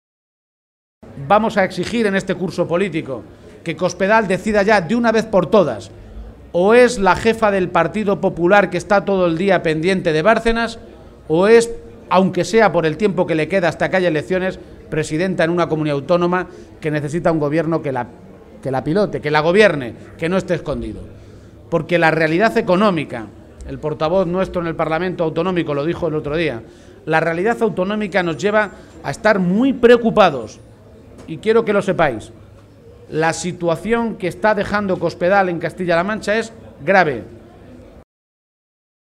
En la atención a medios de comunicación, García Page advirtió que el PSOE de Castilla-La Mancha está dispuesto a seguir el camino marcado por los socialistas en Madrid «para impedir que algo que nos ha costado tanto construir como es la sanidad de la que nos sentimos tan orgullosos se la acaben apropiando unos pocos para hacer negocio».